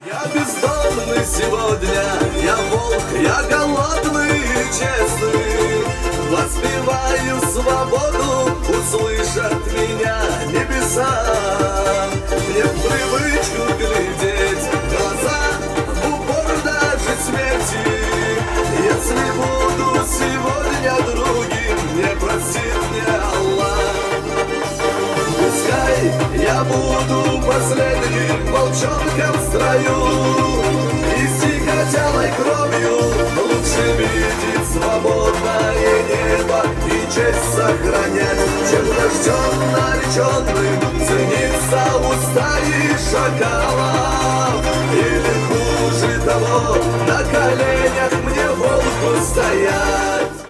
громкие
быстрые
кавказские